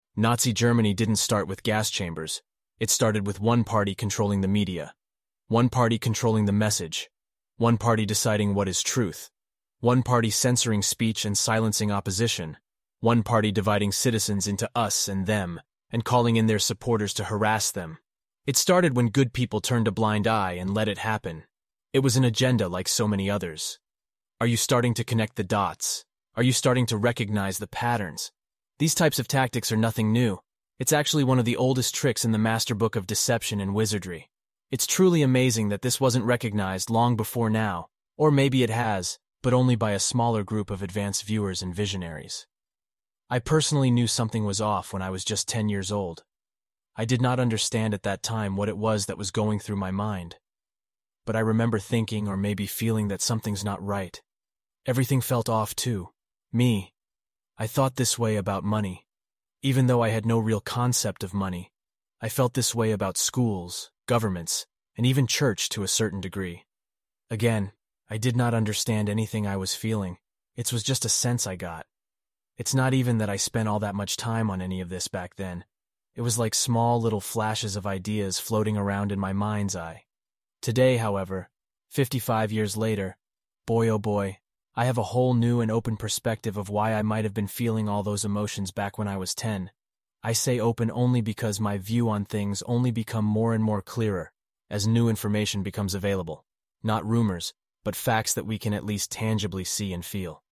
Below, is an audio file I placed here, it was something I wrote up and one day was playing around with Text to audio AI on some website and I put this in and this is what came back, I thought it was sort of cool so I inserted it. let me know what you think.